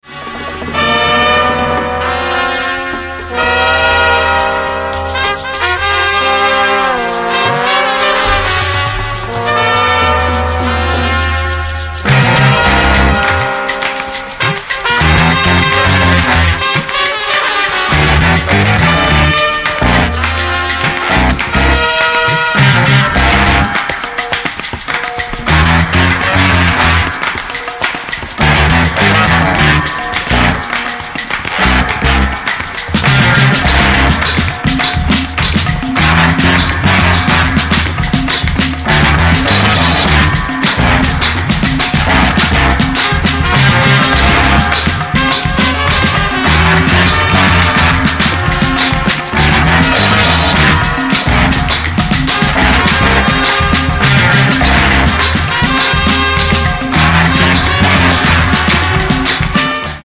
Grammy Award Winning trumpt player for Yoko Ono